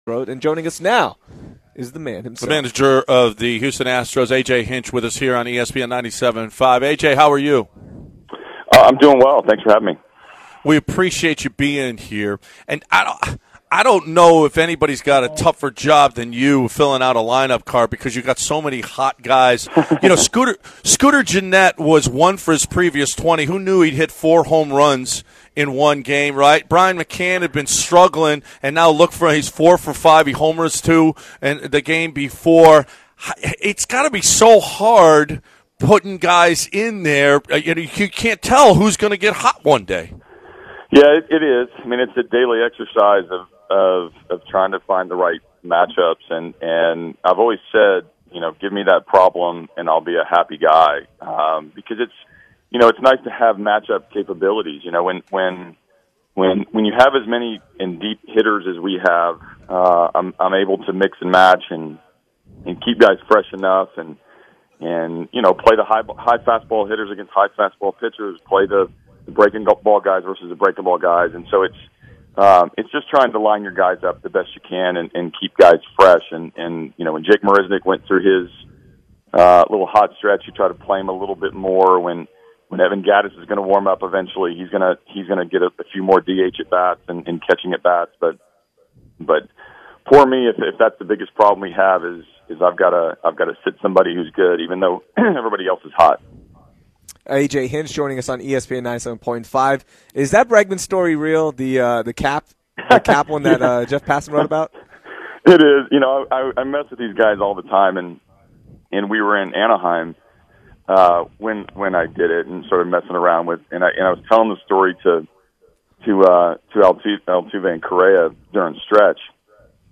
AJ Hinch Interview